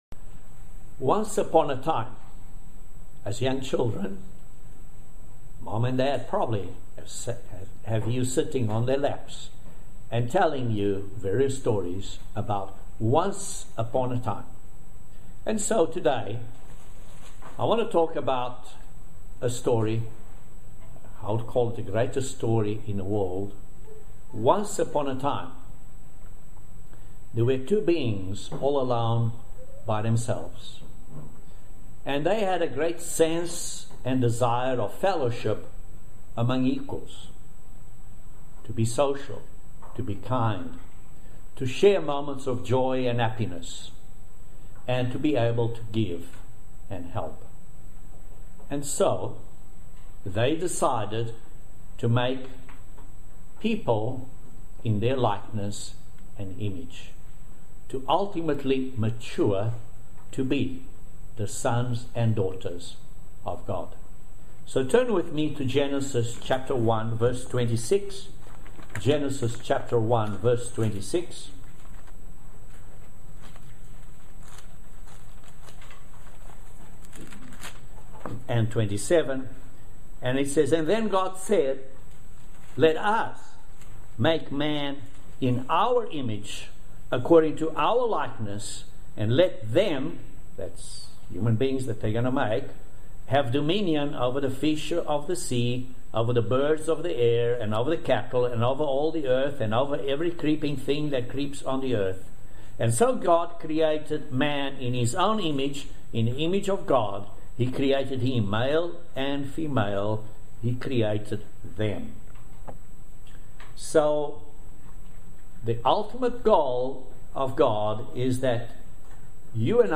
Join us for this amazing video sermon about God's Holy Spirit. In our day to day lives , do we choose the wrong tree, like Adam and Eve did?